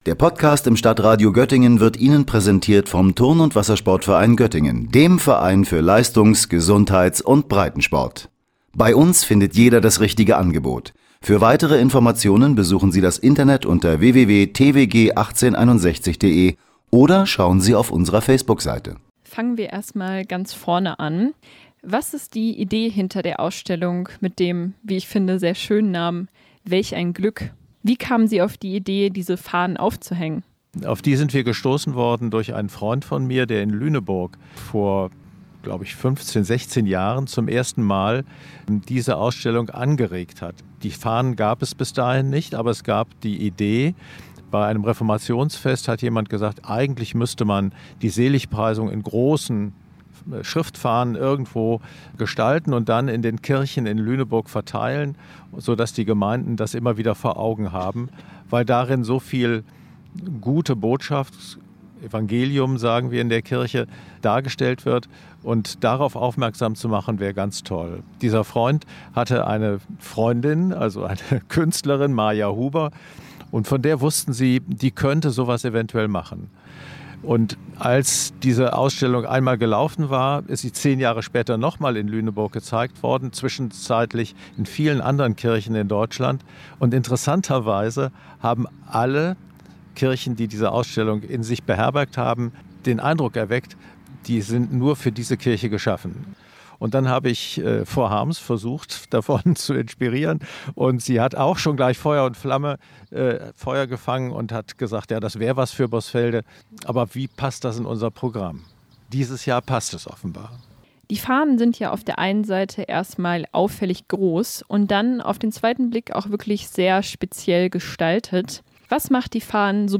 beide sind an der Organisation der Ausstellung beteiligt.